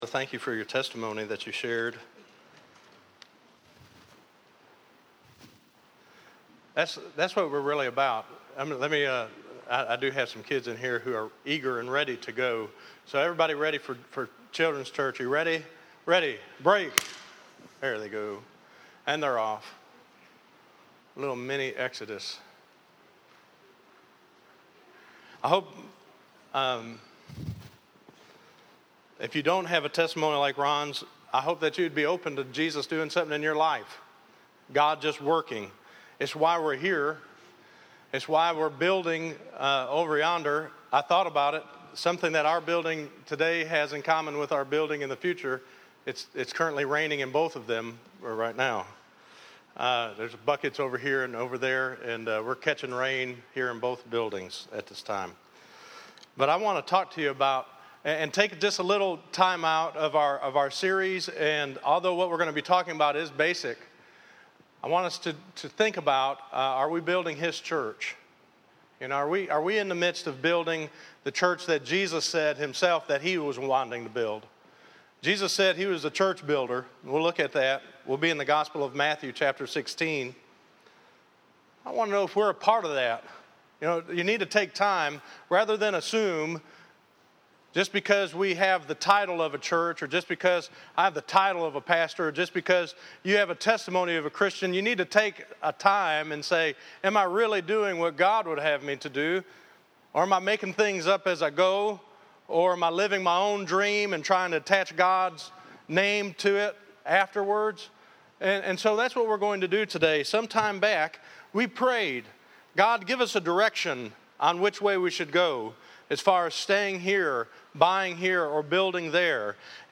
Listen to Are we building HIS Church - 11_16_14_Sermon.mp3